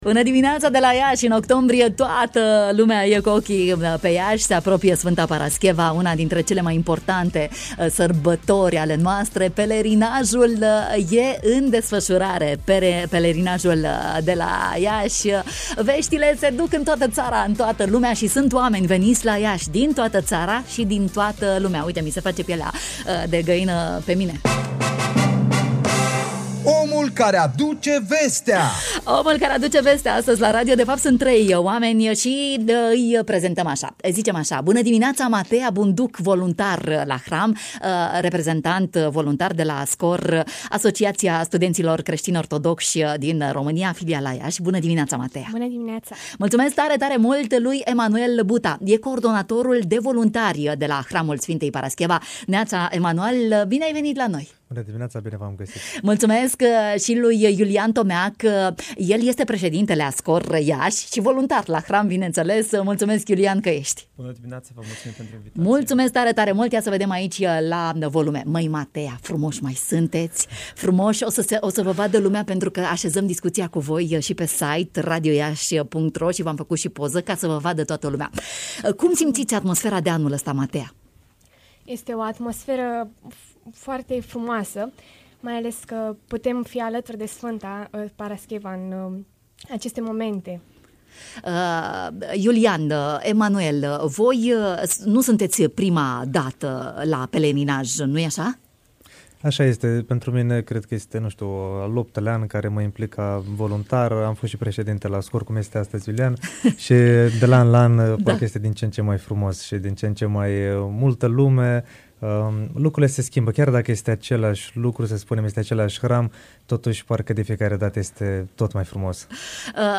în direct la matinalul de la Radio România Iași